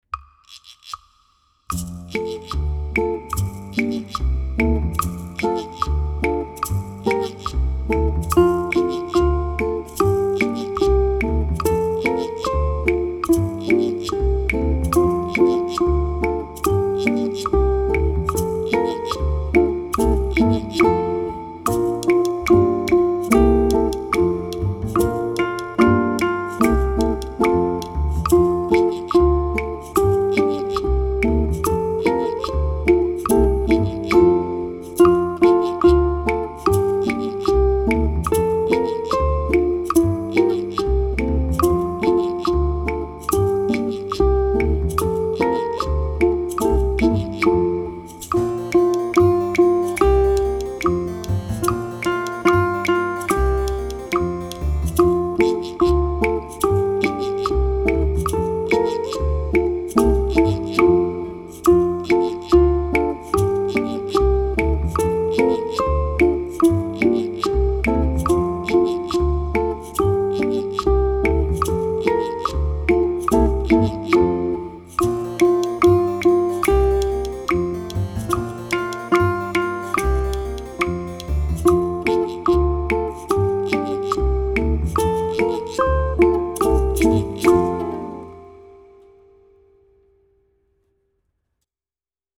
On Avignon Bridge (Sur le pont d'Avignon) is a cheerful French folk song about dancing on a medieval bridge—Pont d'Avignon (also Pont Saint-Bénézet)—on the Rhône river in France.
On Avignon Bridge consists mostly of stepwise melody, eighth note driven rhythm and 4-bar phrases.
The first four measures consist of an introduction made wholly of chords.
ʻukulele